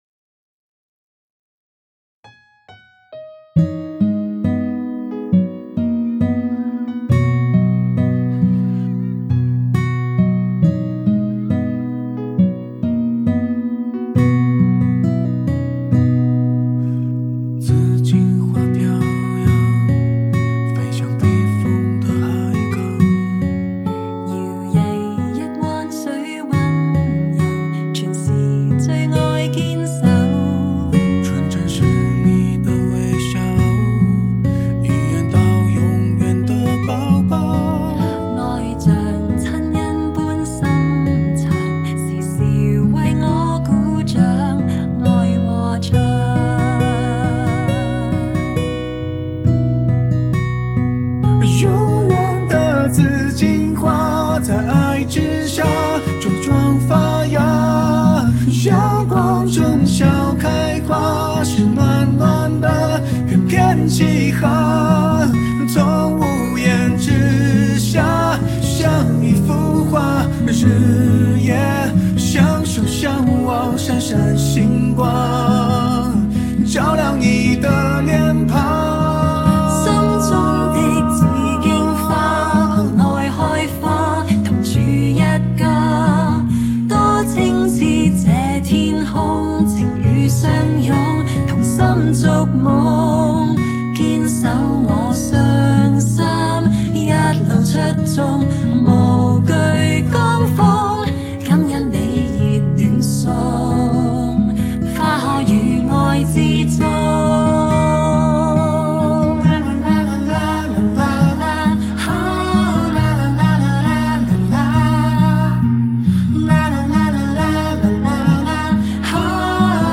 谱内音轨：吉他人声歌词
曲谱类型：弹唱谱